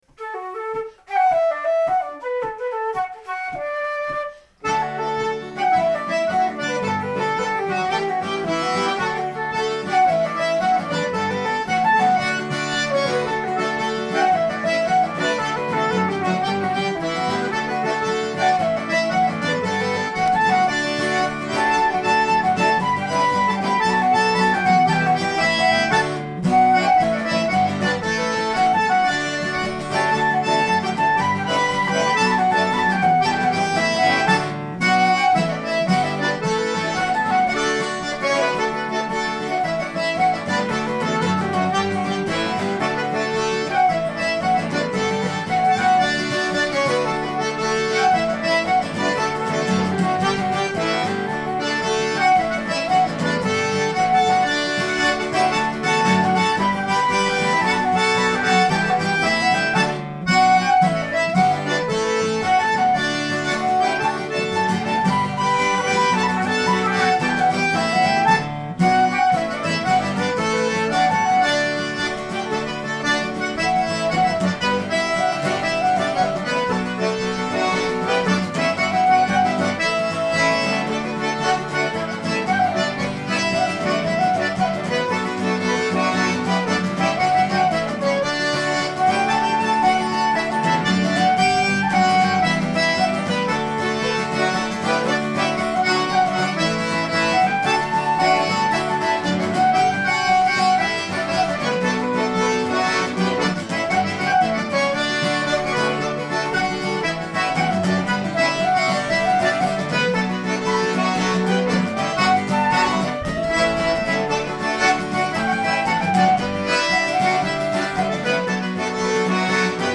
We are an acoustic duo and trio that plays for English barndances.
Great music, with a fresh yet traditional sound.